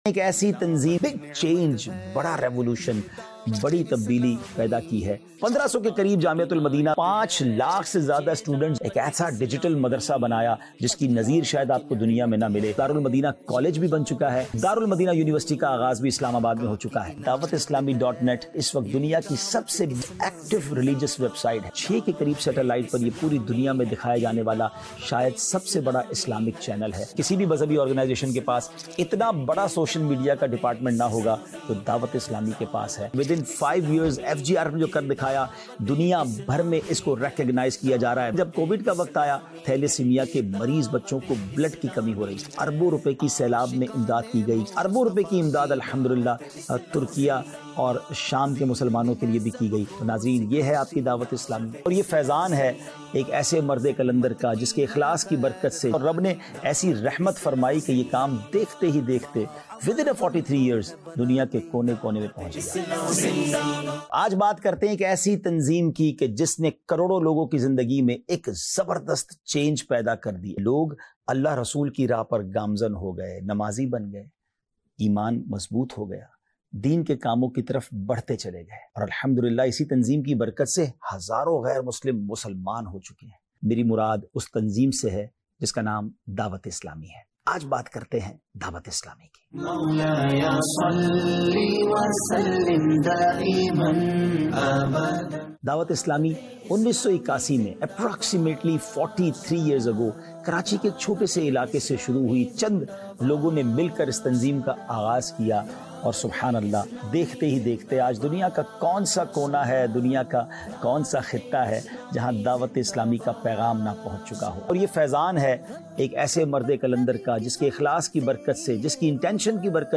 Introduction To Dawateislami | Documentary 2025